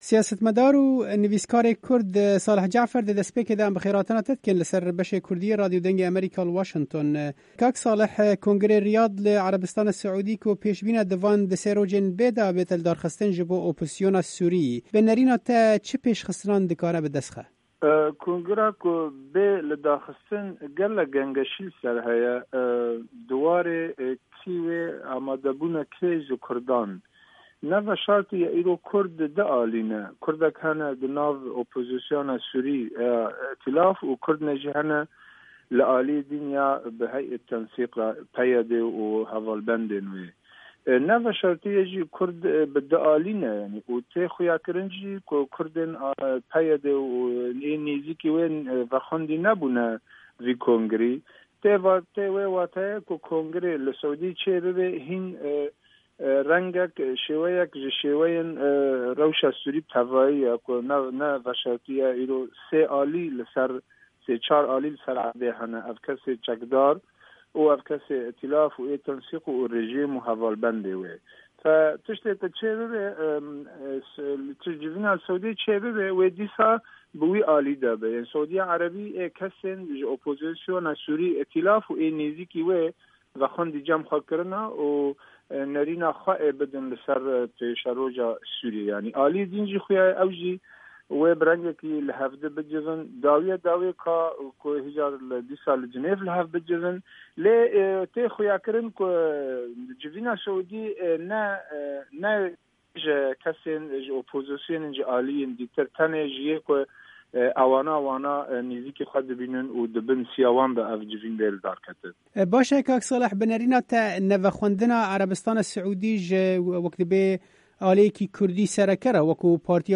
hevpeyvînekê